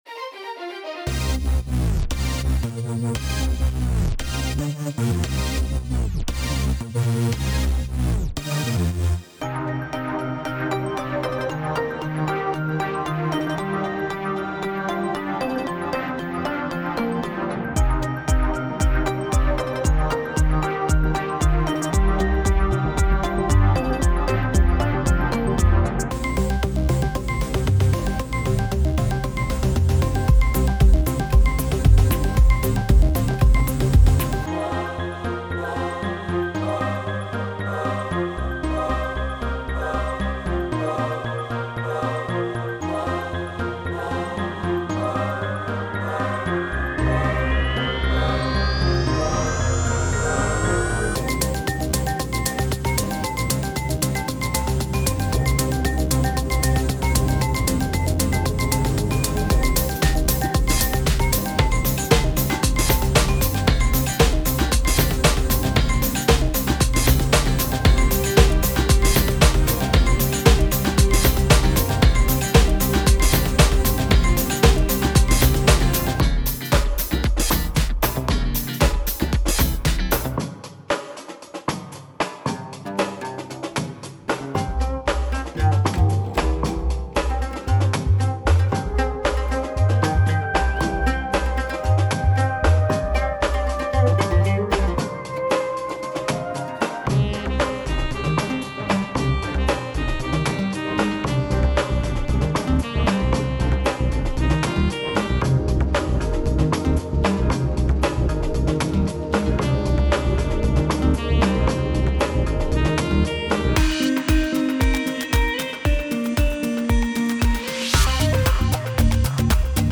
Two instrumental tracks